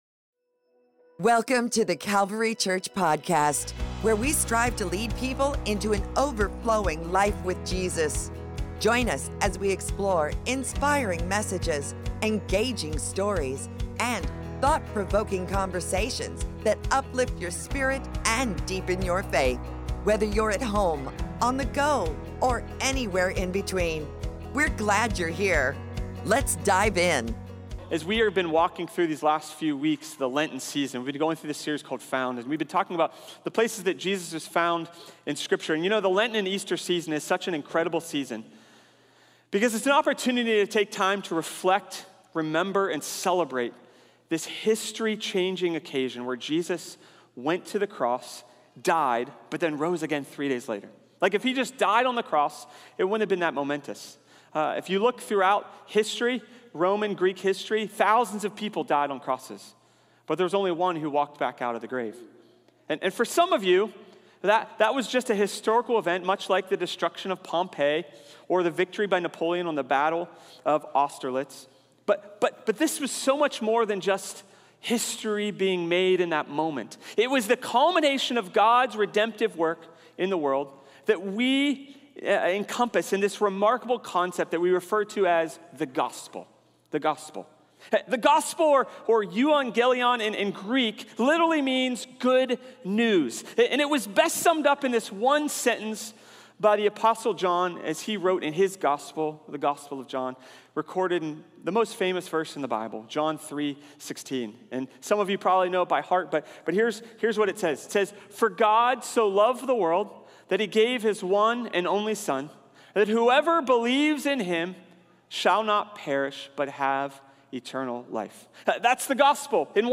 In this episode of our Found message series, we look at the incredible moment in Matthew 14 when Jesus feeds over five thousand people with just five loaves and two fish.